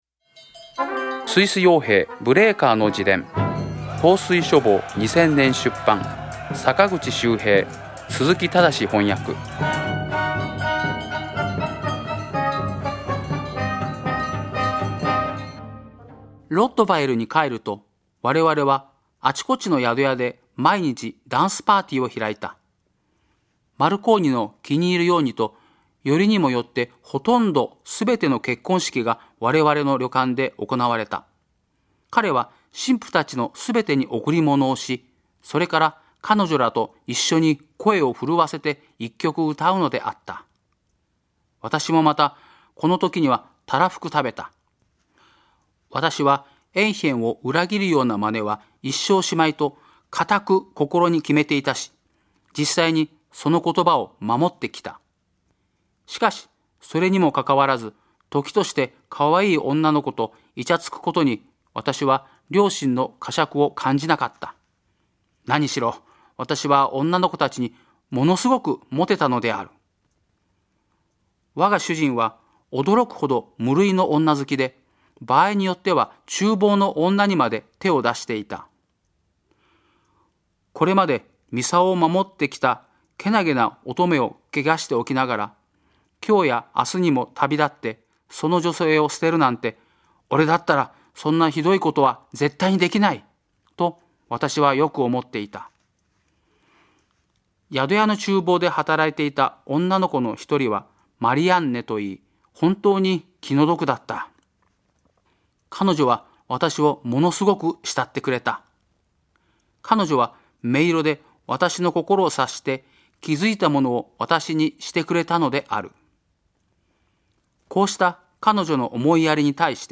朗読『スイス傭兵ブレーカーの自伝』第43回